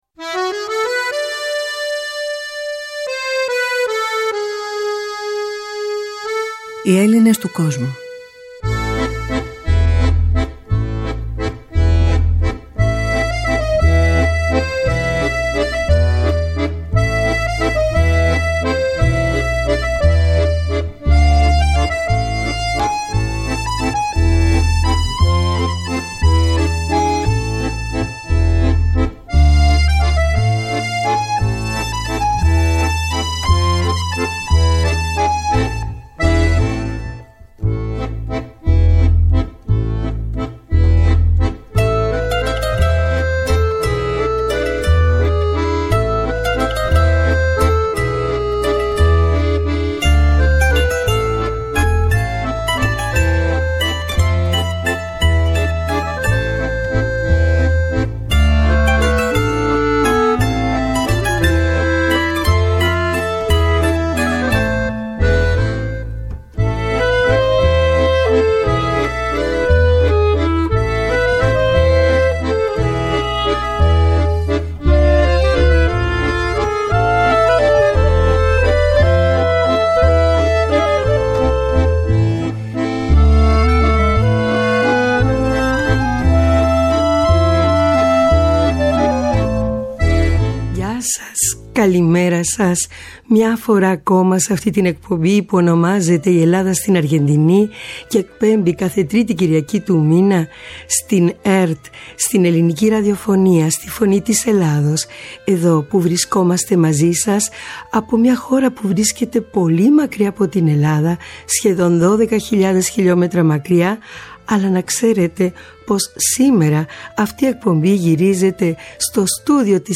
Οι δύο κυρίες ξετύλιξαν την ιστορία της κοινότητας και θα αναφερθούν στους στόχους και τις δράσεις τους σήμερα.